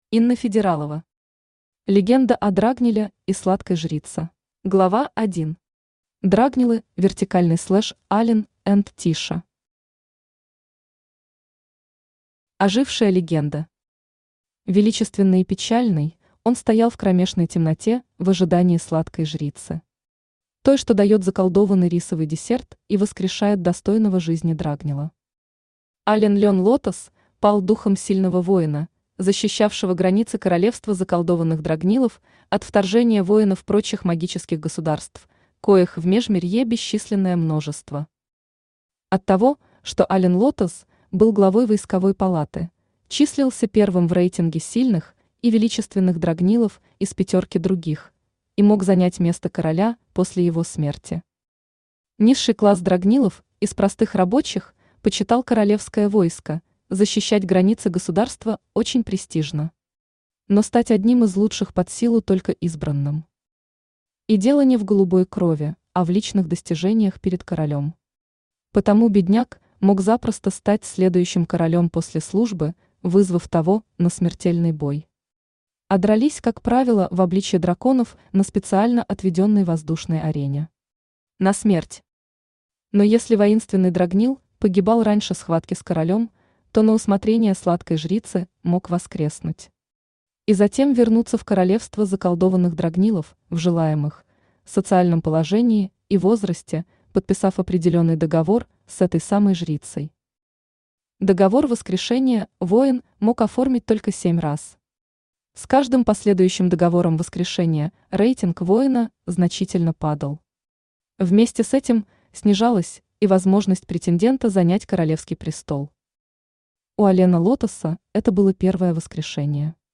Аудиокнига Легенда о драгниле и Сладкой Жрице | Библиотека аудиокниг
Aудиокнига Легенда о драгниле и Сладкой Жрице Автор Инна Федералова Читает аудиокнигу Авточтец ЛитРес.